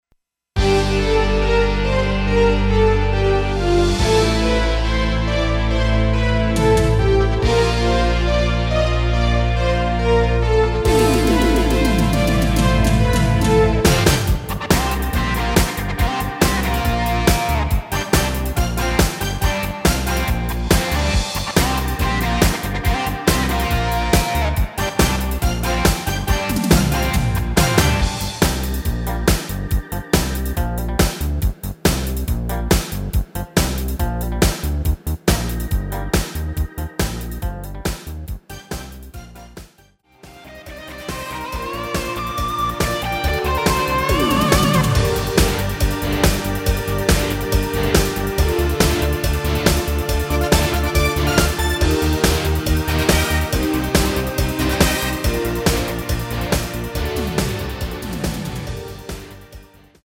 Gm
앞부분30초, 뒷부분30초씩 편집해서 올려 드리고 있습니다.
중간에 음이 끈어지고 다시 나오는 이유는